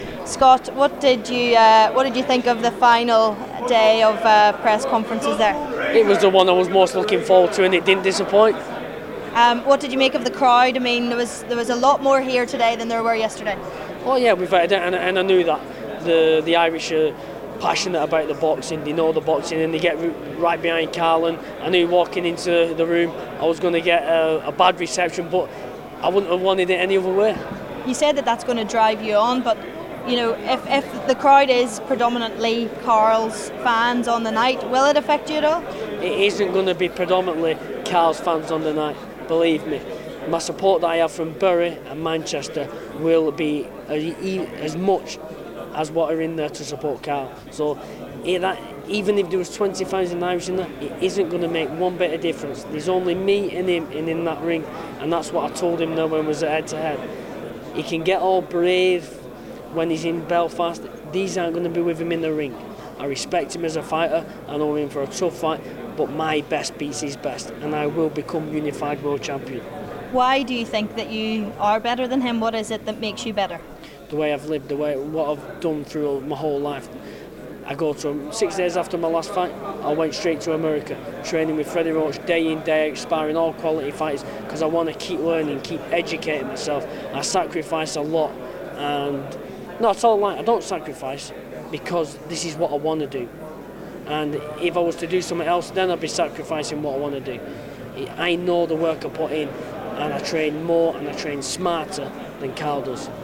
Scott Quigg speaking to U105 ahead of his fight with Carl Frampton